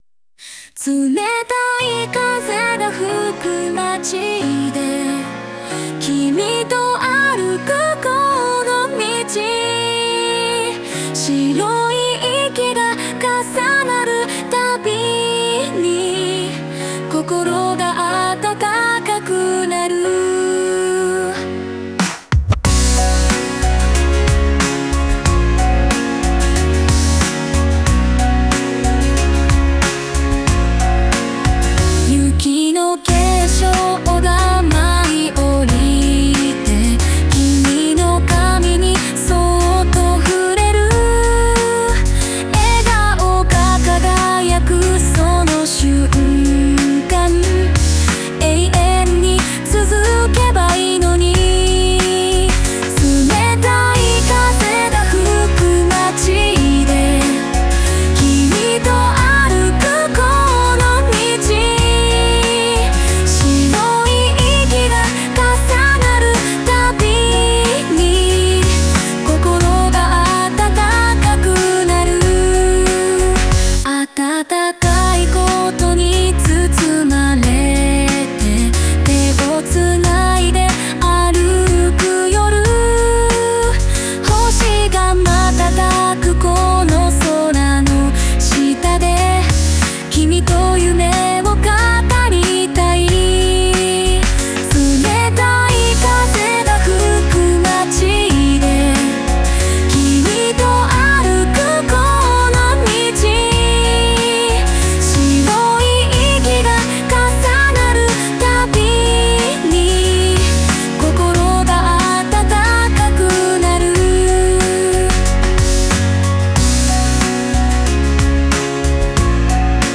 ボーカリスト
J-Pop, バラード 活動開始
その澄んだ歌声と豊かな表現力で知られる日本のボーカリストです。